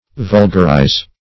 Vulgarize \Vul"gar*ize\, v. t. & i. [imp. & p. p. Vulgarized;